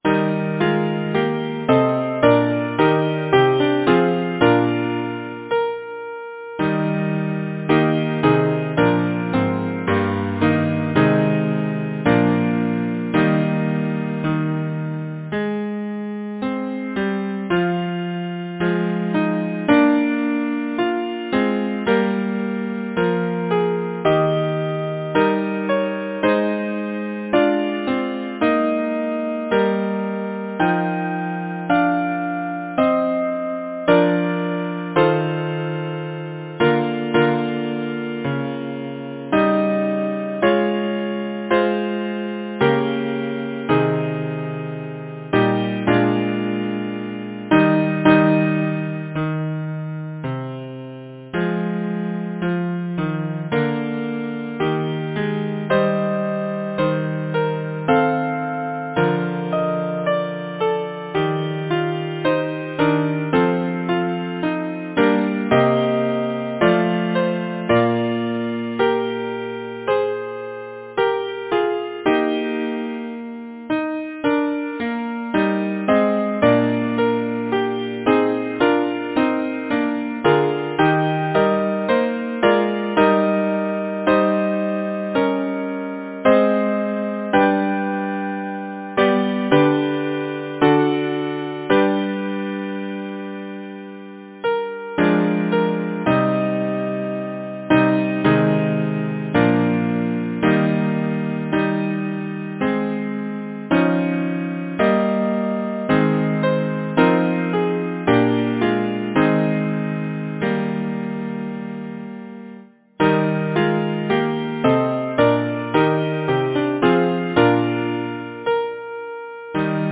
Title: To Chloris Composer: Charles Villiers Stanford Lyricist: Edmund Waller Number of voices: 4vv Voicing: SATB Genre: Secular, Partsong, Madrigal
Language: English Instruments: A cappella